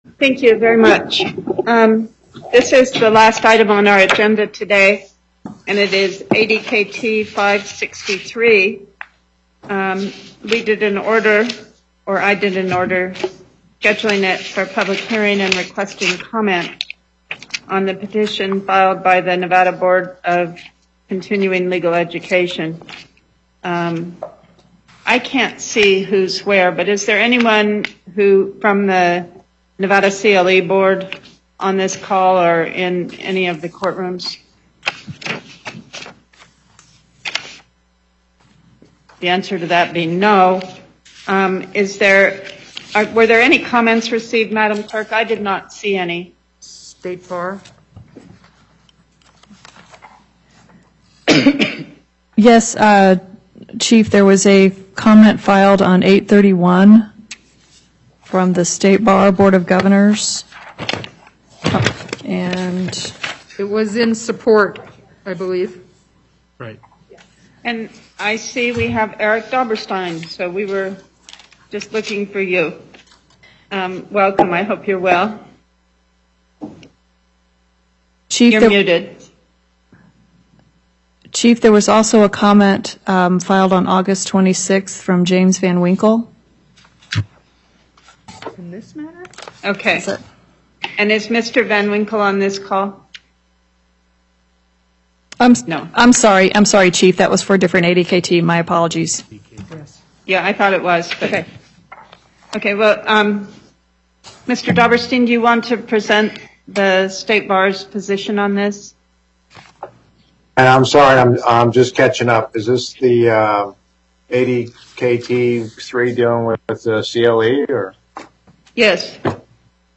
Date: 09/08/2020 Time: 3:00 P.M. Location: Carson City and Las Vegas
Before En Banc, Chief Justice Pickering Presiding